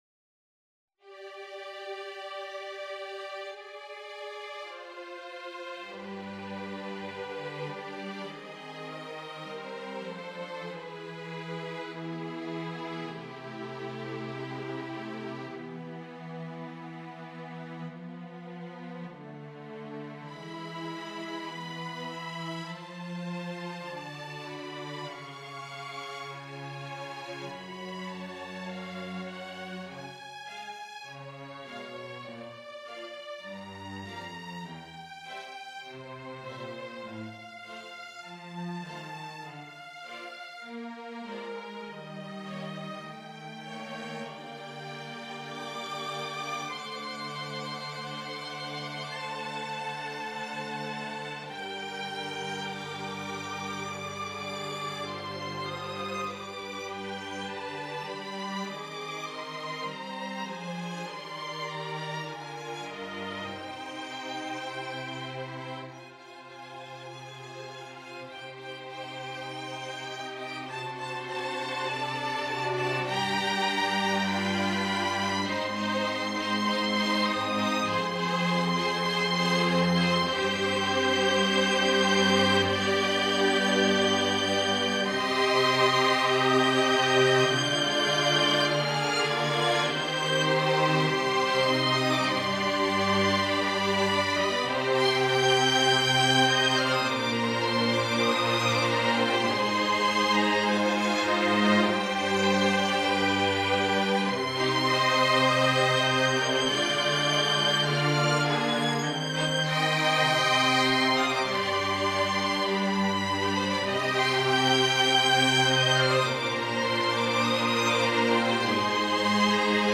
Ruthless Love - Orchestral and Large Ensemble
This is a my first piece for String ensemble and solo violin. I will say the harmony is a fusion of neo-classical, late romantic and asian (japanese) harmonies.